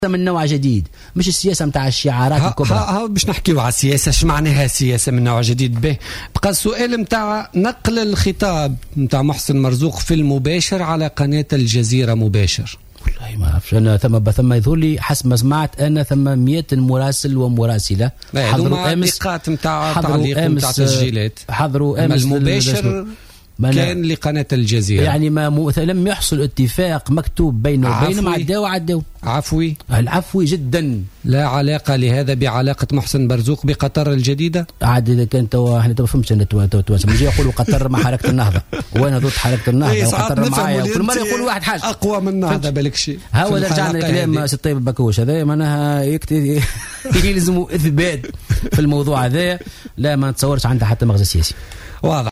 وشرح مرزوق ضيف برنامج "بوليتيكا" اليوم الاثنين أن نقل خطابه أمس الأحد في المباشر على قناة "الجزيرة مباشر" كان عفويا ولم يكن نتيجة اتفاق مسبق، وفق تعبيره.